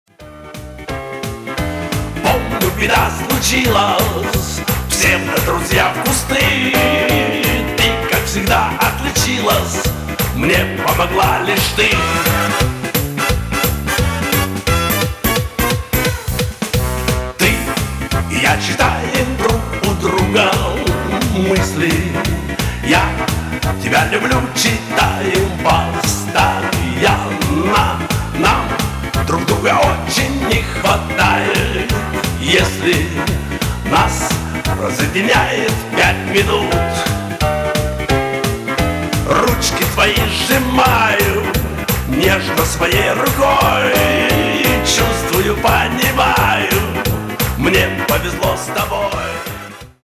• Качество: 320, Stereo
мужской вокал
русский шансон